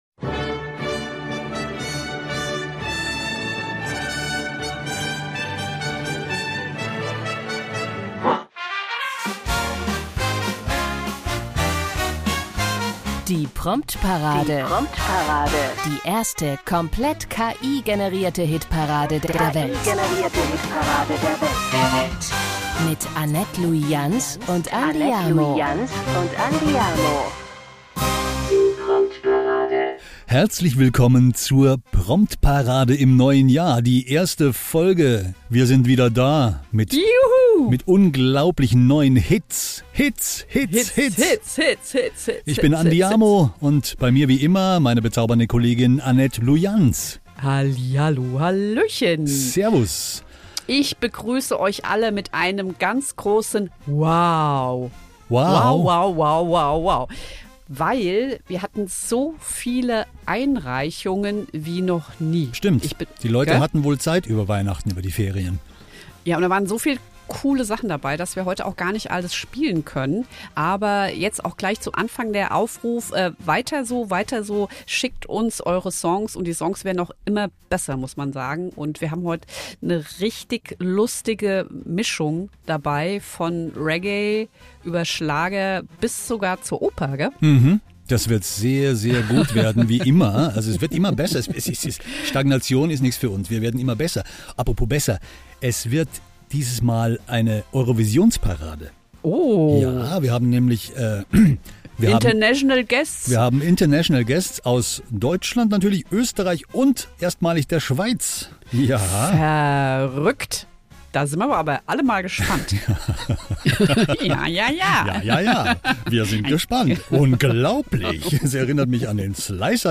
Alle Songs wurden zumindest komplett von einer künstlichen Intelligenz umgesetzt. Es wurde weder etwas eingespielt, noch eingesungen.